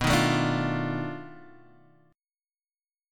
B7#9b5 chord